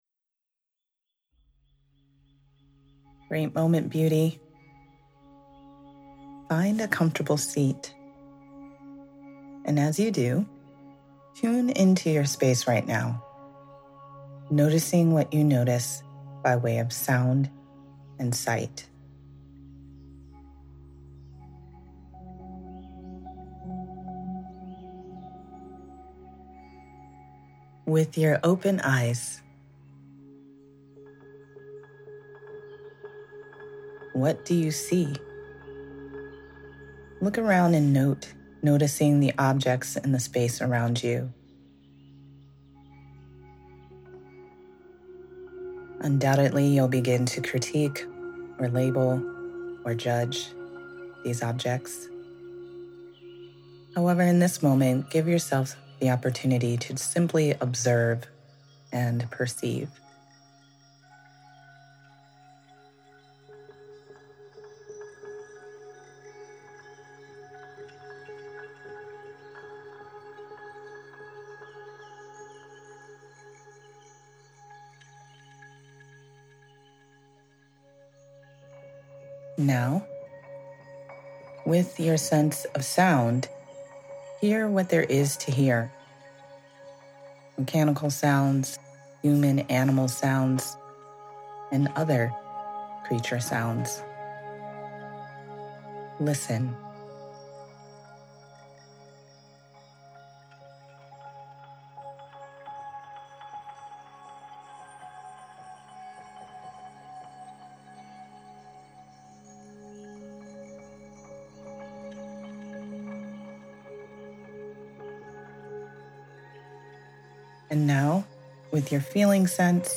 hgxN8zZYTWmljE9RfUK3_Grounding_Cord_Meditation.mp3